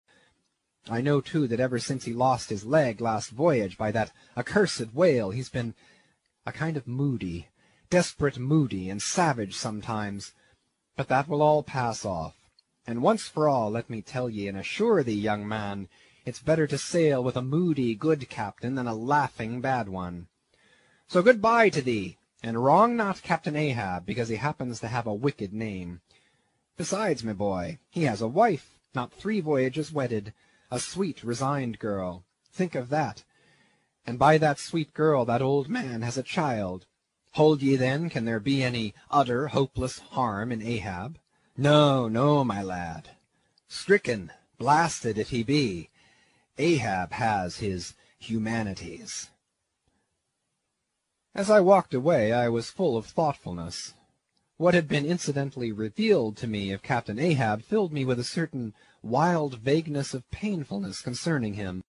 英语听书《白鲸记》第81期 听力文件下载—在线英语听力室